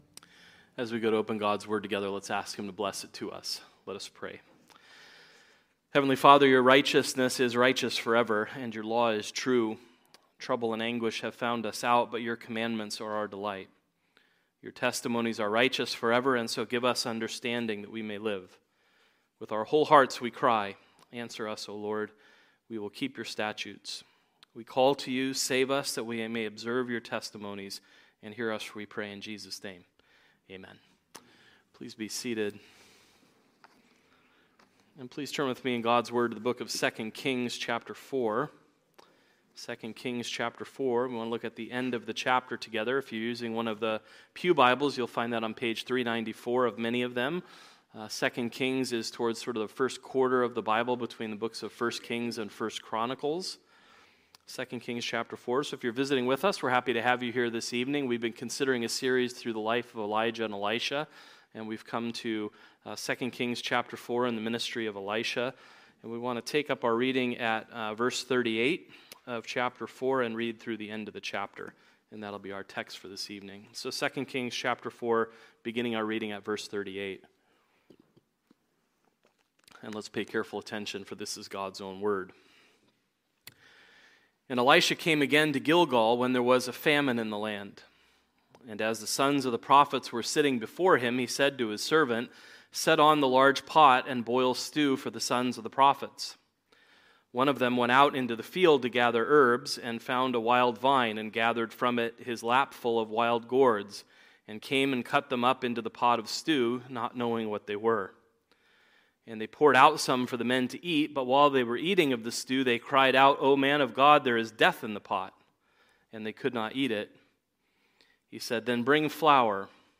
Sermons preached at CURC
Christ-centered, redemptive-historical sermons from the pulpit of CURC, Santee, CA